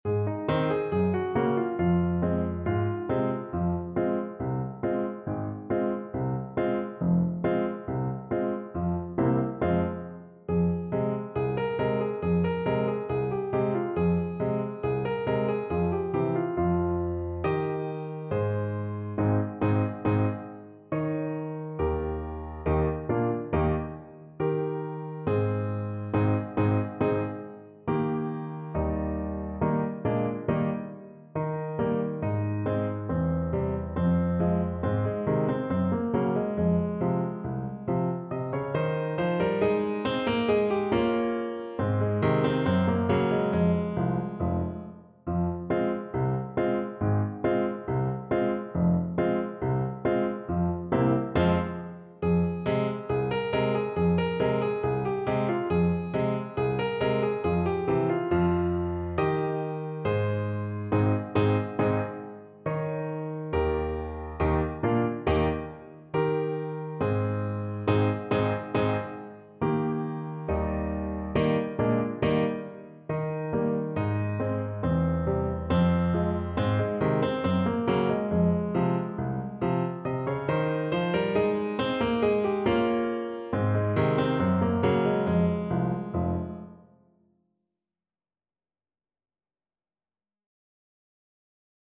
Play (or use space bar on your keyboard) Pause Music Playalong - Piano Accompaniment Playalong Band Accompaniment not yet available transpose reset tempo print settings full screen
French Horn
Traditional Music of unknown author.
F minor (Sounding Pitch) C minor (French Horn in F) (View more F minor Music for French Horn )
Slow =69
2/4 (View more 2/4 Music)
Eb4-Eb5